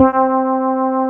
BAS.FRETC4-L.wav